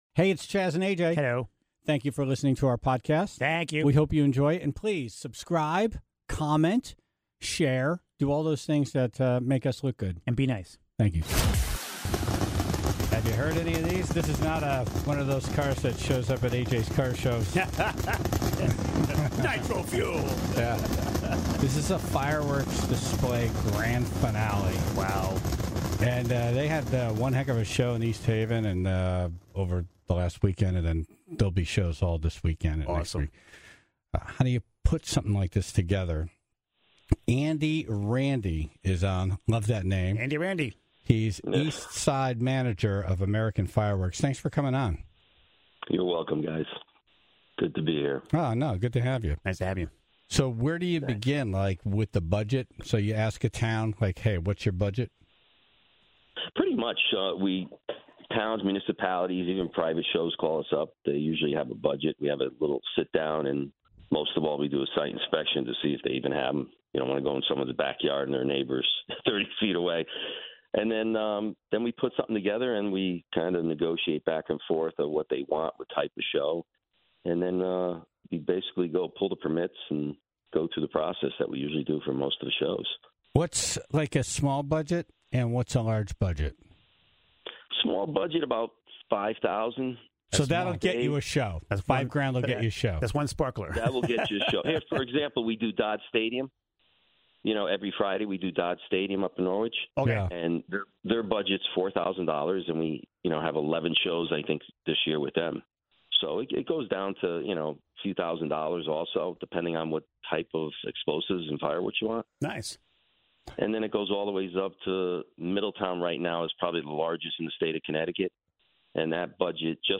(0:00) A recent survey on Buzzfeed compiled a list of "girly" things that masculine men have admitted to liking. The Tribe called in to share their admissions, from baking home-made brownies to taking their weekly bubble bath.